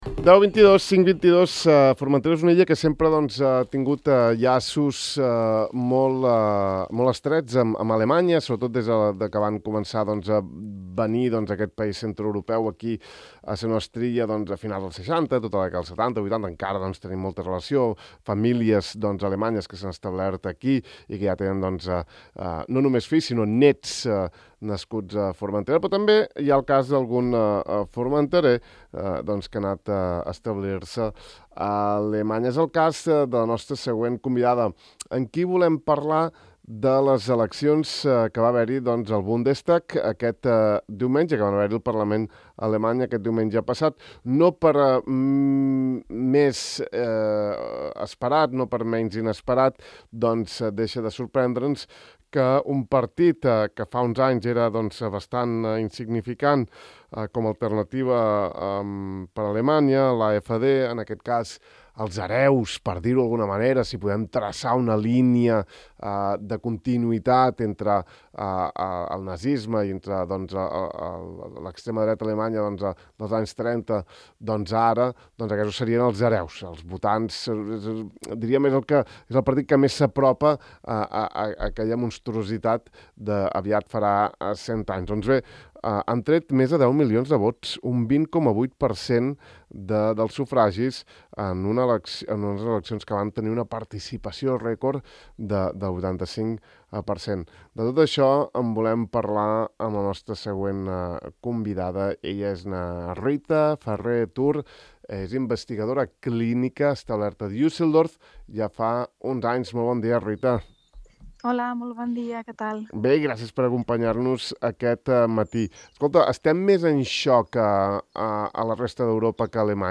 En aquesta entrevista ens ajuda a buscar claus del fenomen social i polític de primer ordre que ha esdevingut també a Alemanya el nacionalpopulisme.